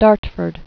(därtfərd)